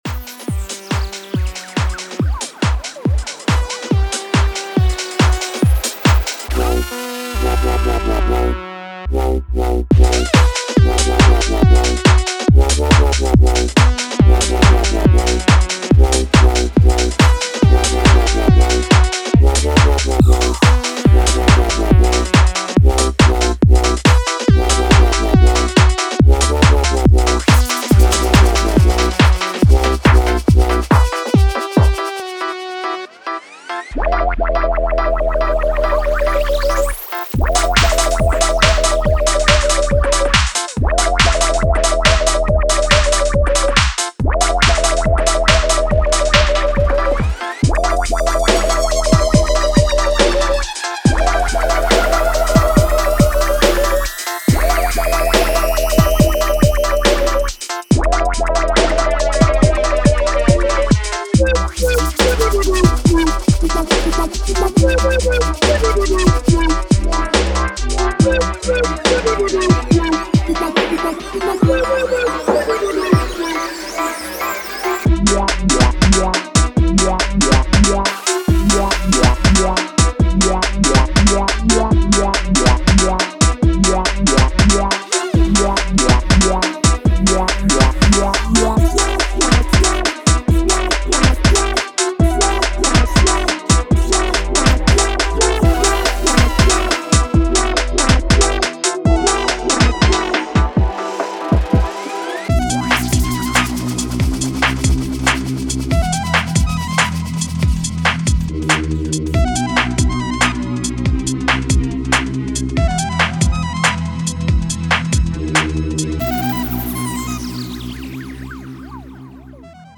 Bass Loops & Bass One-Shots: Deep, gritty basslines and hard-hitting one-shots designed to bring the low-end energy that UK bassline is known for.
Drum Loops: Tight and punchy drum loops featuring booming kicks, sharp claps, and snappy hi-hats to provide the perfect rhythmic foundation.
FX Loops: A variety of risers, impacts, and sweeps that add dynamic transitions and extra flair to your tracks.
Synth Loops & Synth One-Shots: Catchy synth loops and versatile one-shots that range from bright stabs to moody chords, perfect for adding melodic elements to your beats.
Top Loops: Groovy top loops filled with hi-hats, shakers, and percussive elements to give your tracks that driving, danceable feel.
UK-Bassline-Vol-1.mp3